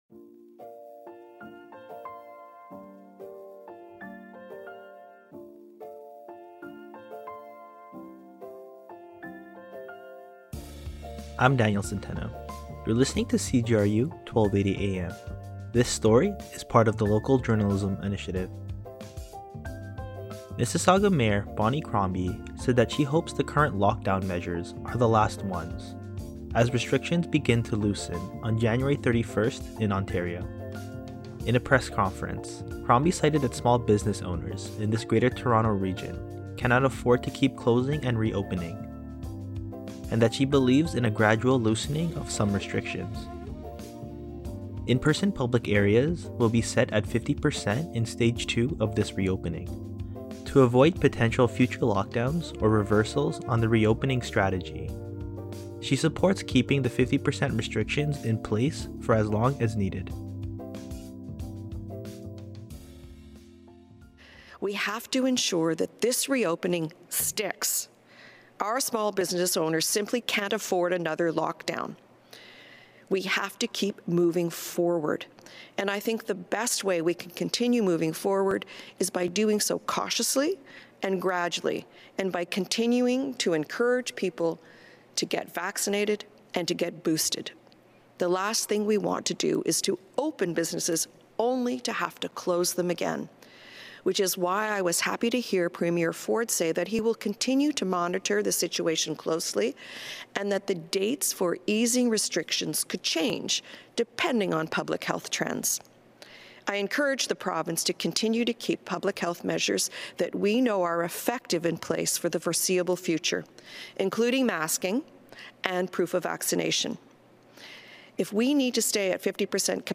In a press conference, Crombie cited that small business owners in this Greater Toronto region cannot afford to keep closing and reopening, but said she believes in a gradual loosening of some restrictions.